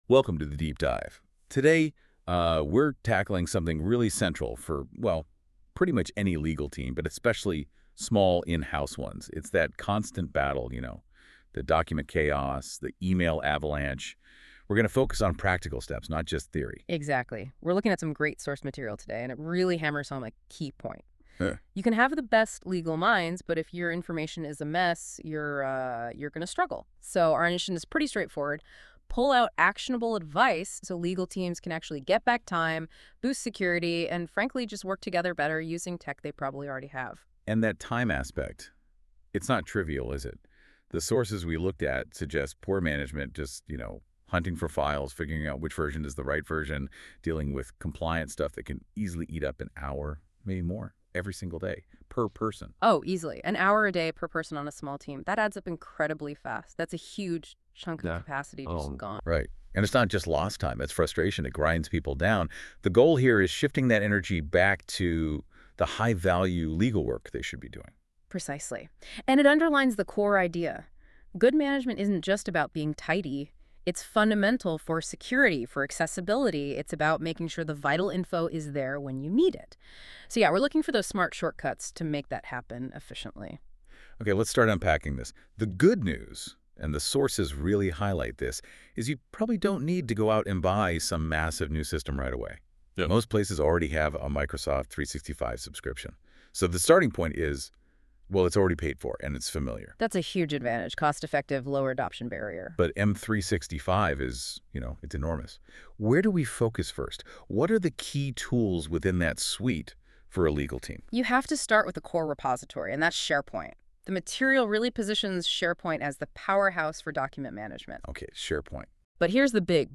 Google Notebook LM - Optimising Document and Email Management For Small Legal Teams.m4a